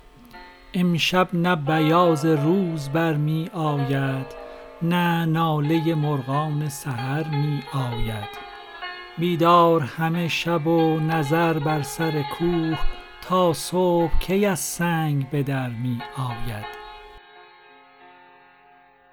رباعی شمارهٔ ۷۶ به خوانش